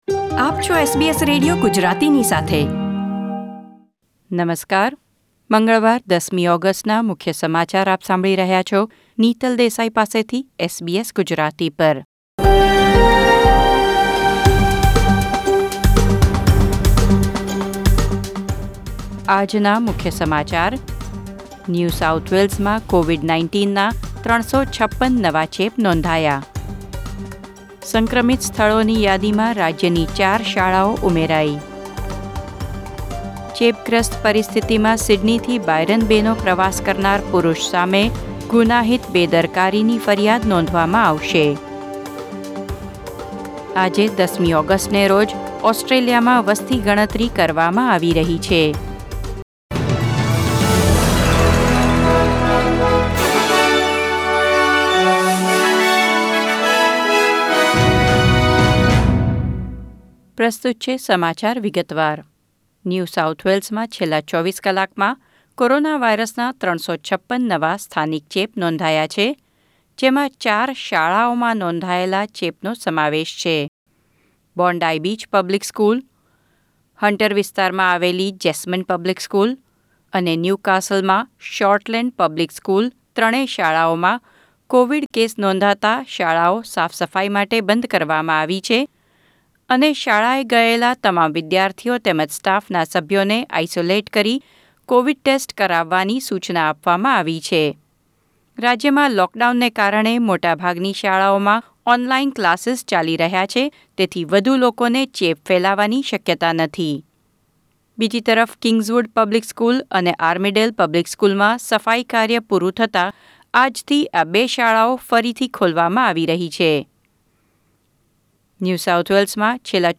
SBS Gujarati News Bulletin 10 August 2021